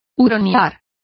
Complete with pronunciation of the translation of ferreting.